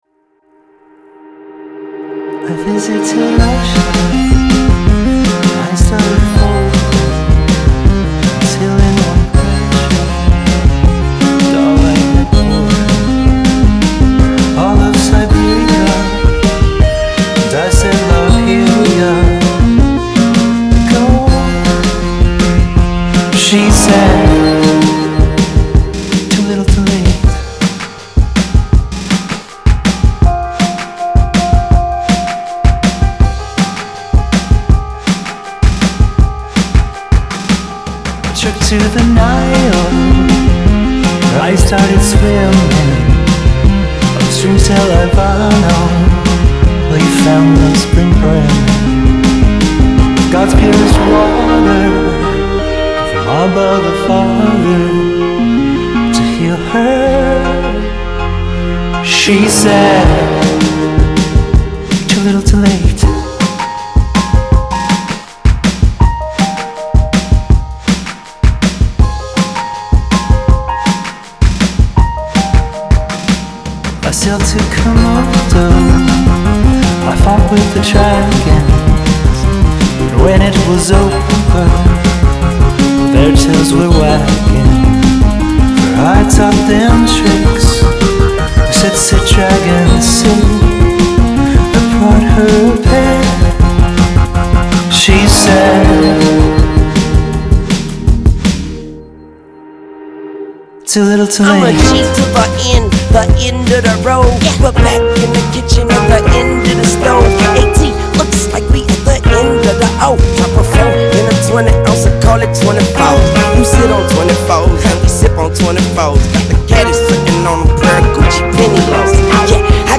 A nice blend of melancholic pop and brooding rock
indie rock project